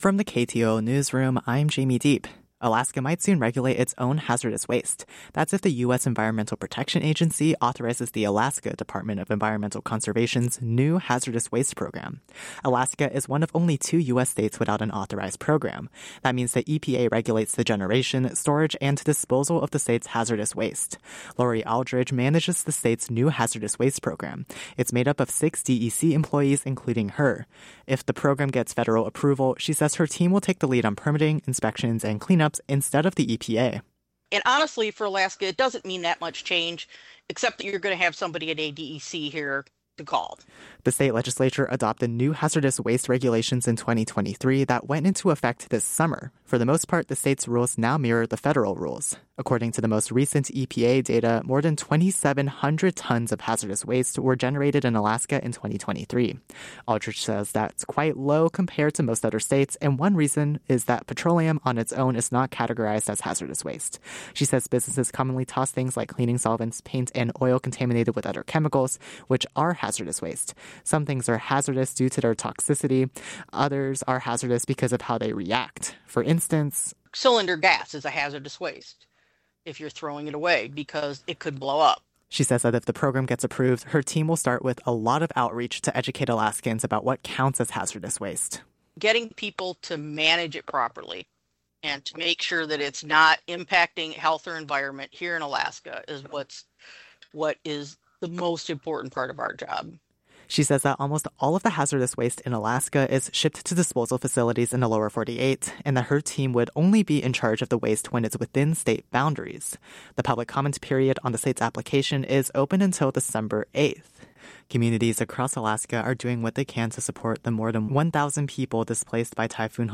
Newscast – Wednesday, Nov. 5, 2025